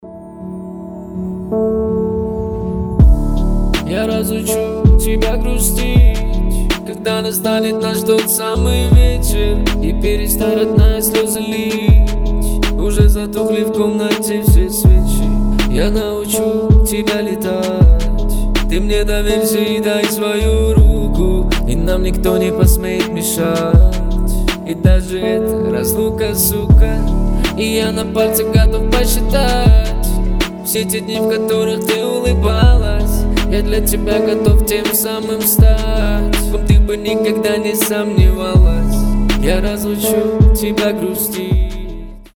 лирика
русский рэп
спокойные
пианино